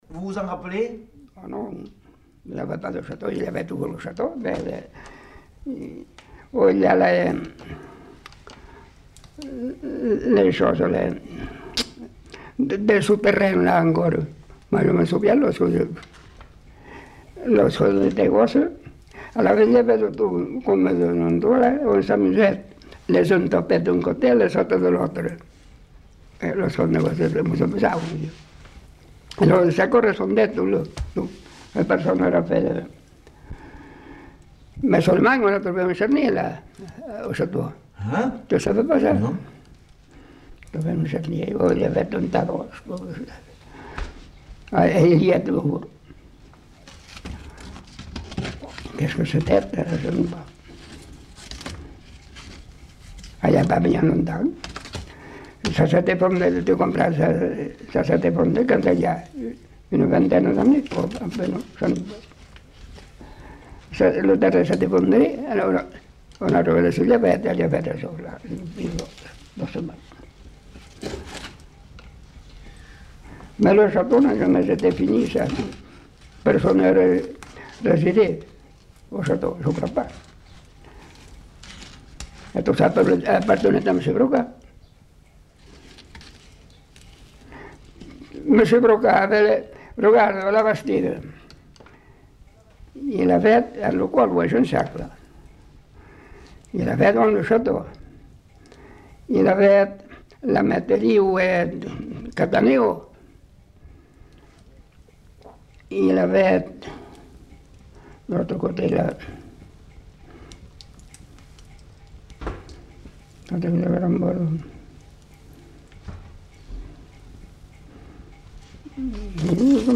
Lieu : Pompiac
Genre : témoignage thématique